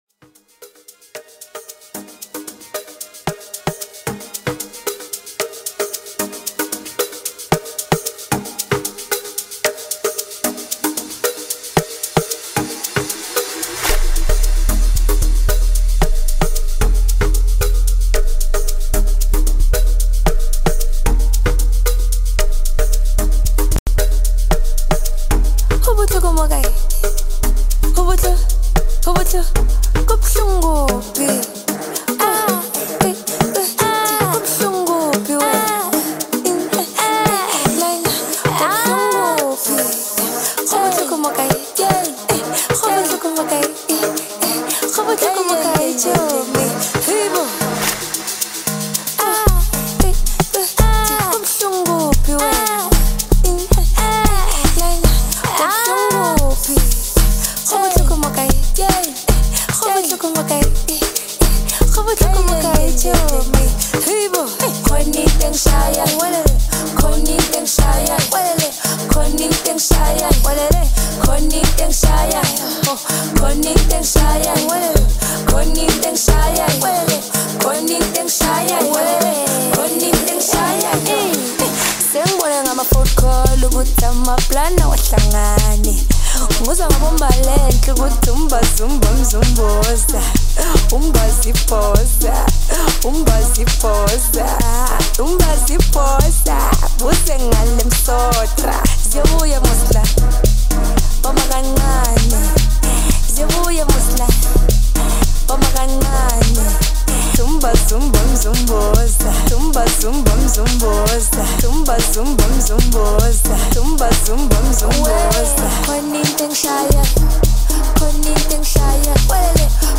Home » DJ Mix » Hip Hop